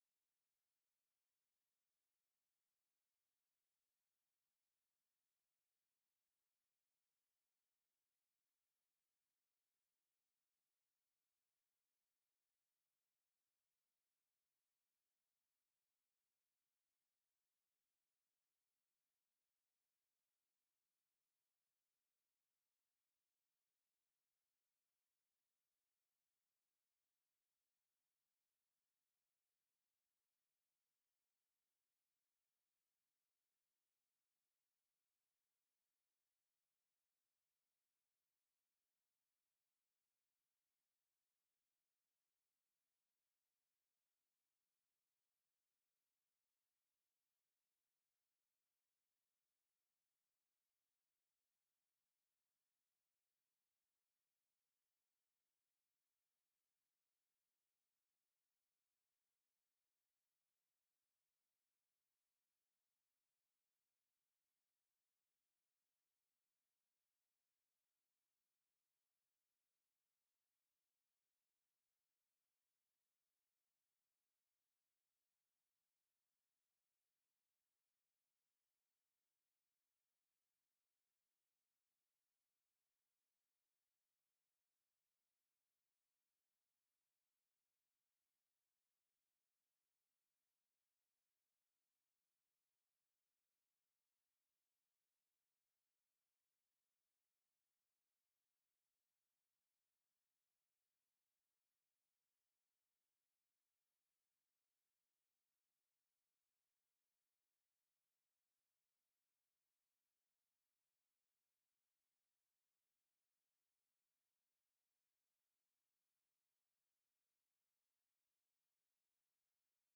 Conversation with CLARK CLIFFORD, October 29, 1964
Secret White House Tapes